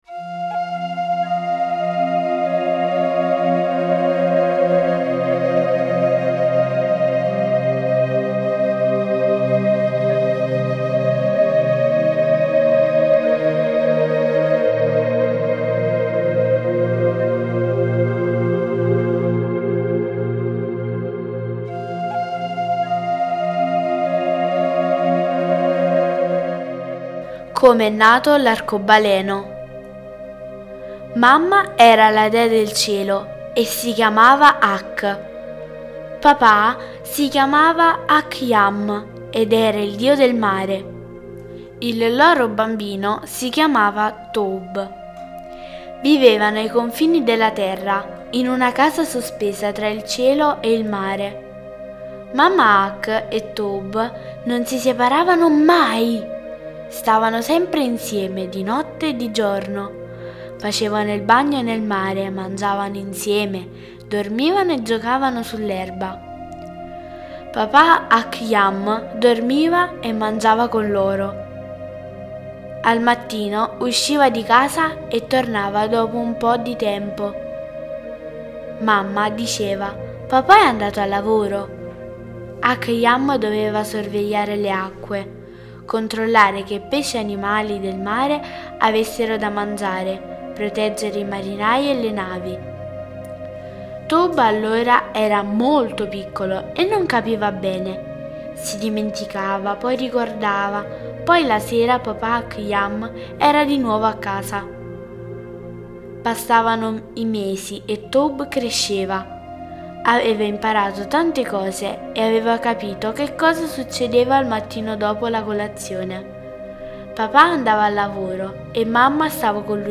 mamma legge la fiaba
Le favole della buonanotte